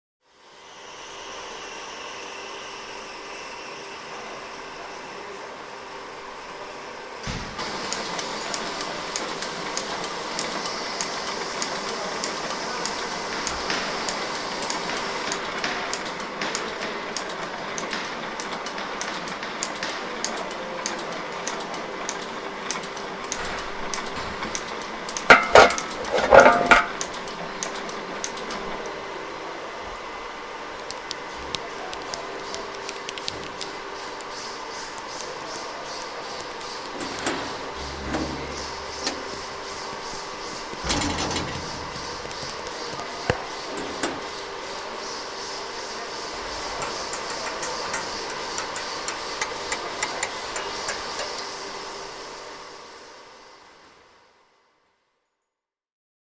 Workshop Puterring
Ambient
Workshop Puterring is a free ambient sound effect available for download in MP3 format.
yt_oWgC6DRcWfM_workshop_puterring.mp3